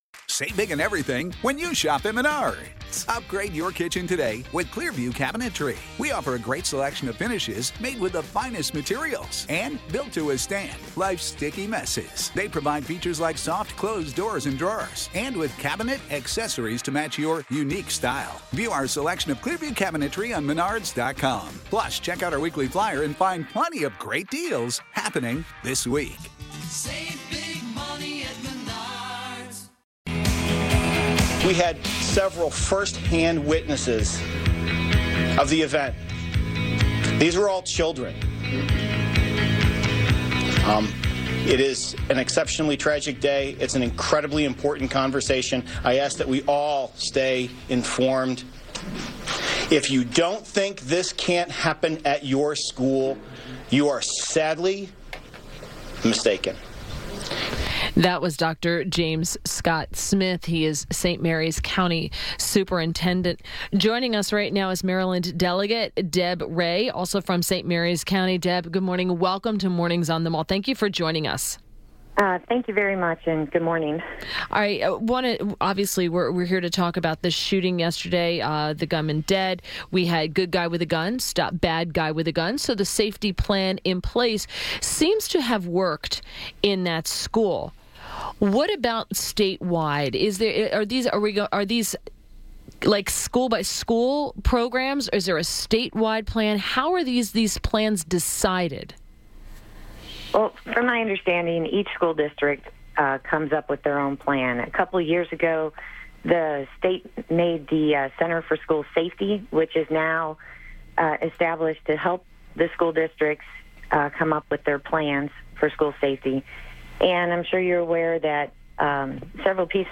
WMAL Interview - MD. Del. DEBORAH REY - 03.21.18